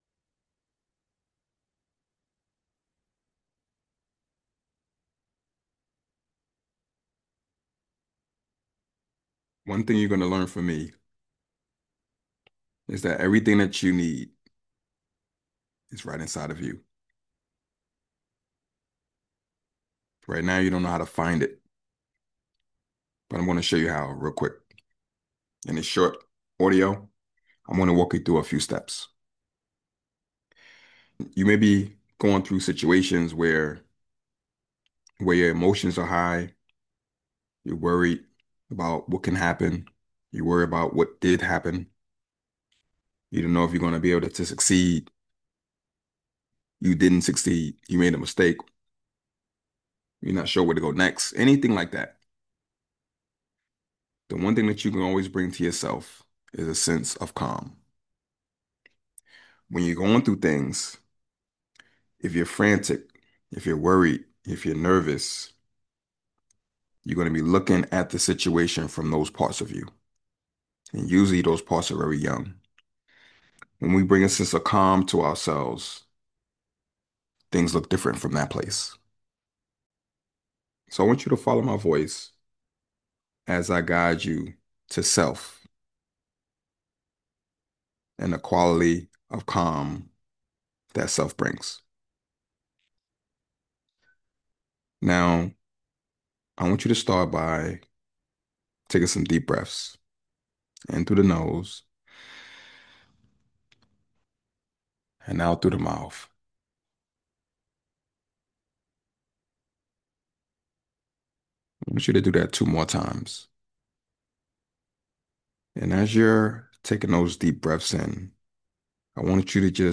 Listen to my audiobook chapter from Freeing Self: IFS Beyond the Therapy Room — hear how IFS helped me overcome personal trauma and how I support others.